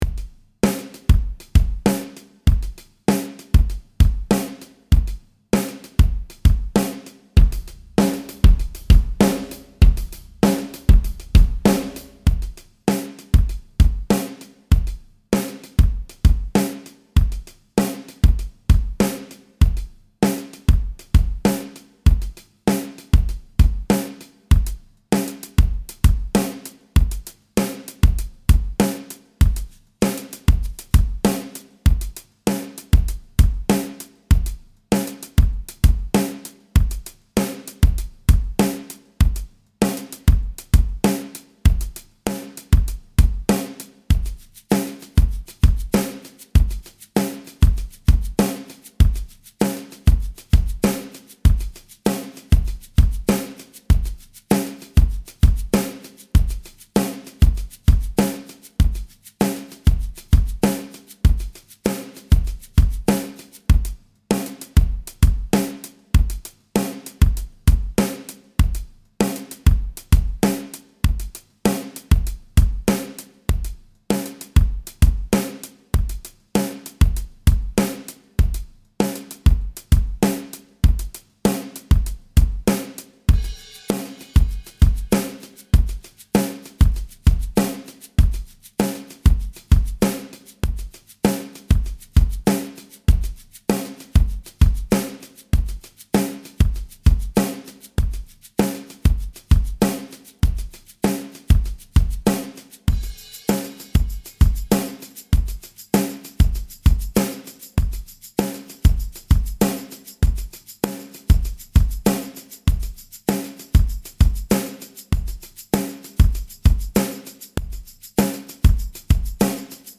All-Of-Us-Drum-Loop-.mp3